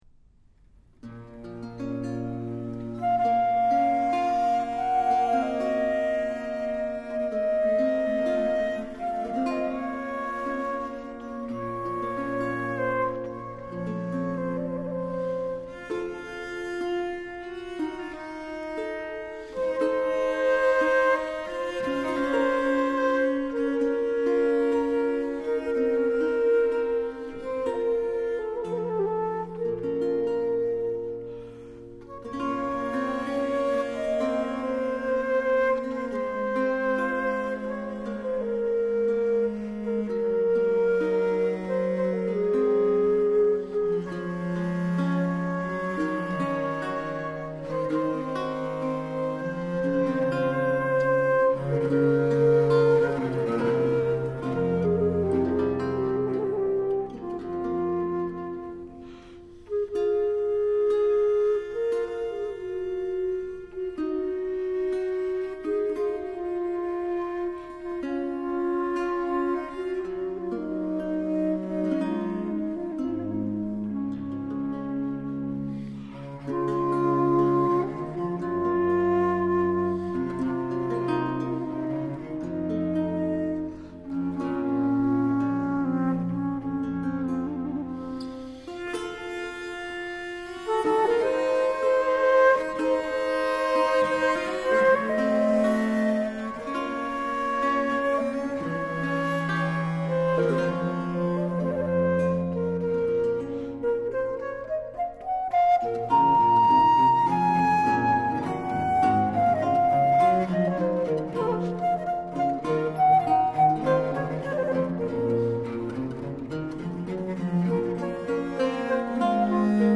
Suites et sonates pour flûte et musette
• Musique française des XVIIe et XVIIIe siècles | partition
vielle à roue
viole de gambe
theorbe, guitare
clavecin